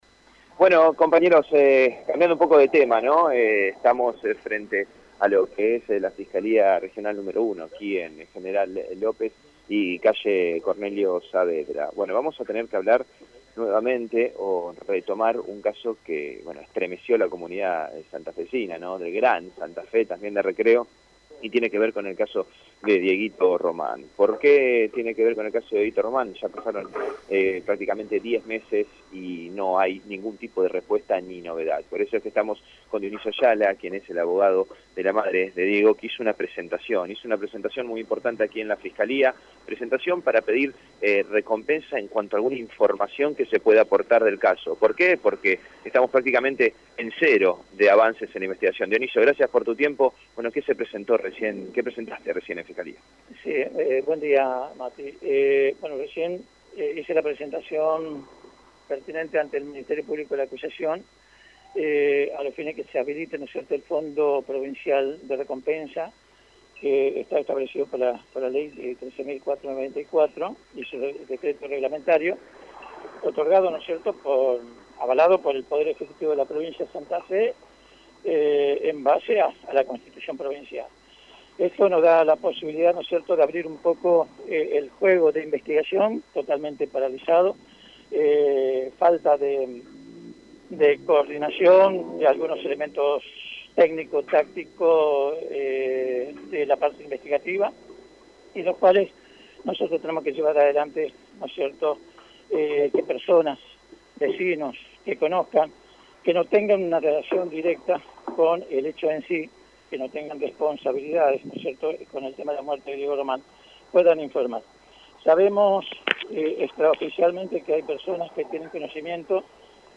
En diálogo con el móvil de Radio EME